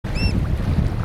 Ostrero Negro (Haematopus ater)
Fase de la vida: Adulto
Localidad o área protegida: Camarones
Condición: Silvestre
Certeza: Observada, Vocalización Grabada
ostrero-negro.mp3